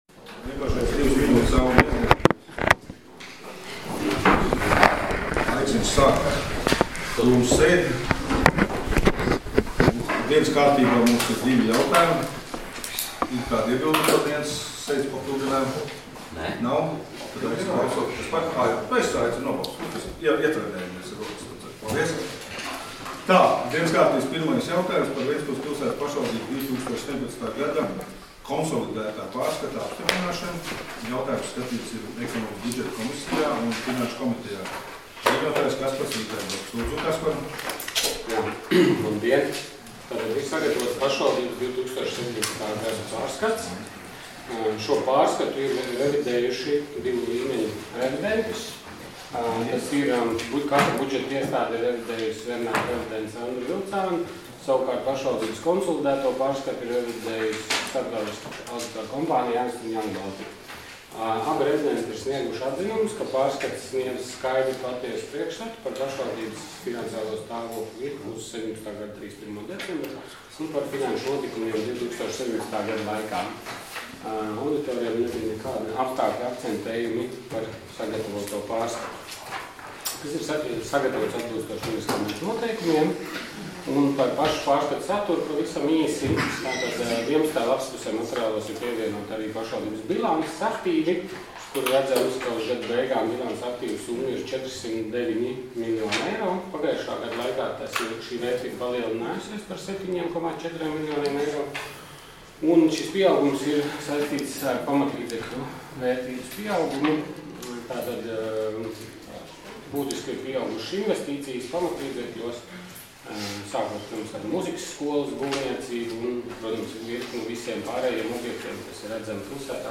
Domes sēdes 27.04.2018. audioieraksts